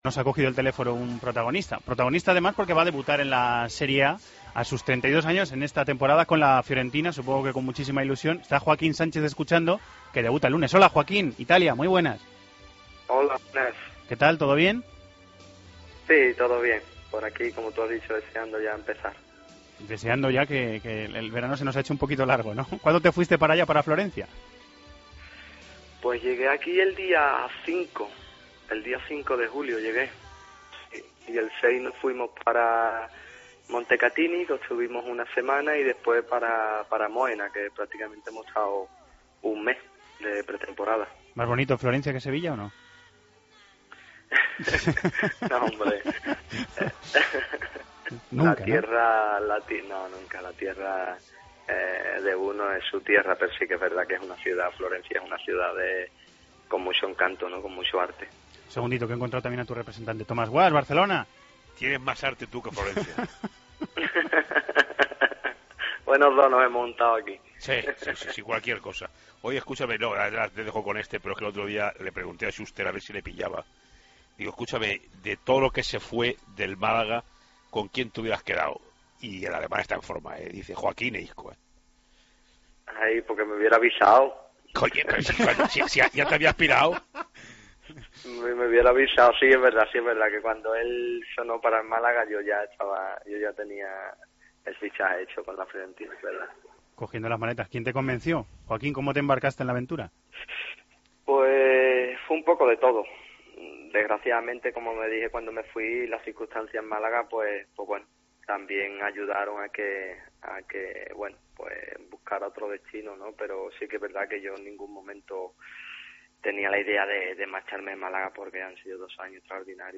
Entrevista a Joaquín, en This is Fútbol